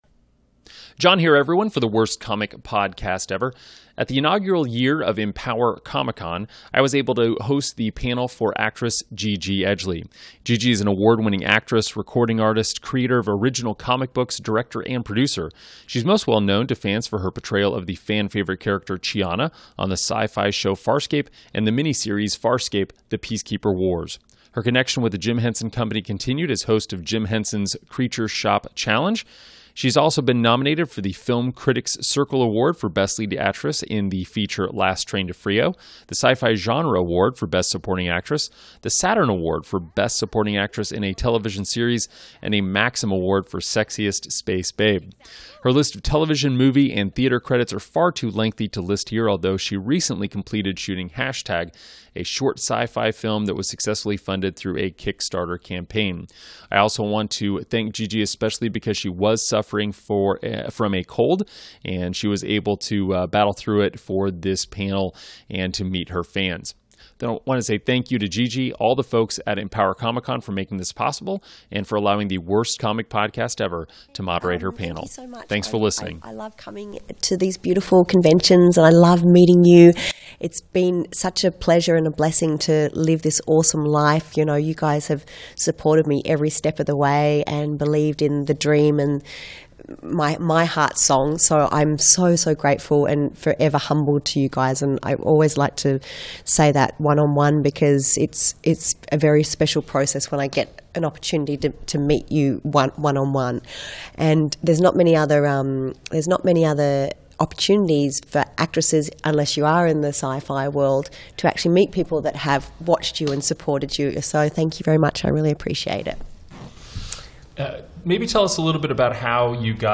Gigi Edgley Panel from Empower Comic Con
gigi-edgley-interview.m4a